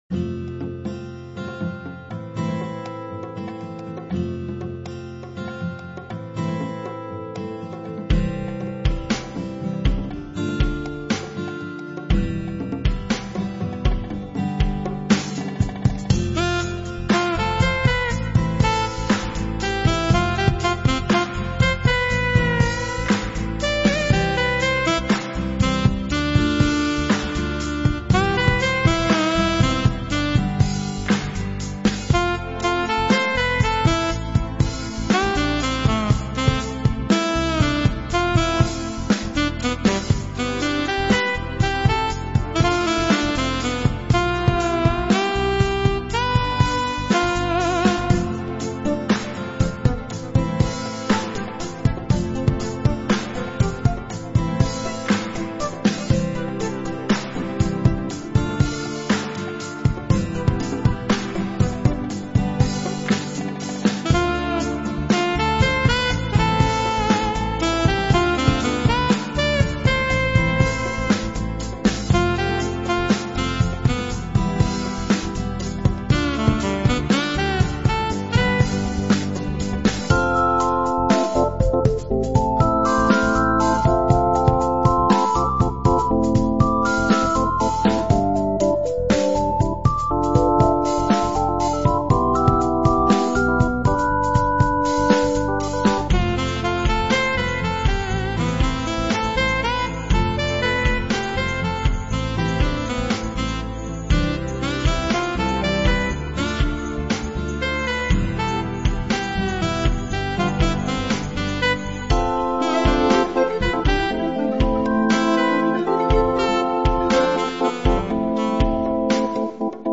Relaxed Instrumental Pop with Synth Saxophone Lead